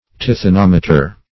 Search Result for " tithonometer" : The Collaborative International Dictionary of English v.0.48: Tithonometer \Tith`o*nom"e*ter\, n. [Tithonic + -meter.] An instrument or apparatus for measuring or detecting tithonicity; an actinometer.